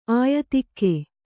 ♪ āyatikke